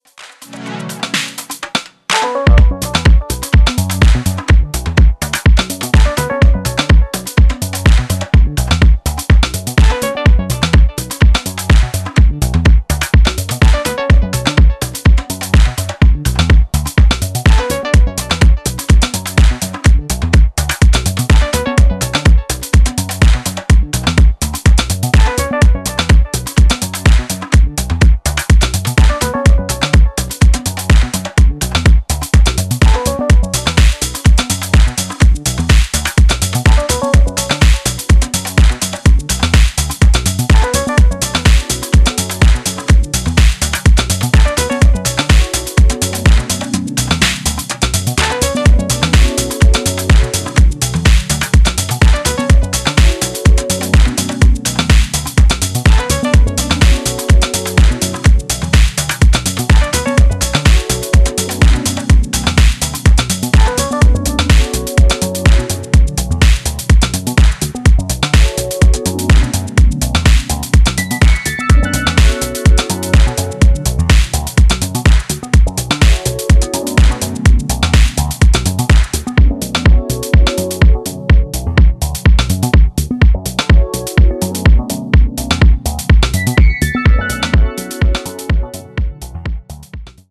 ジャンル(スタイル) DEEP HOUSE / TECHNO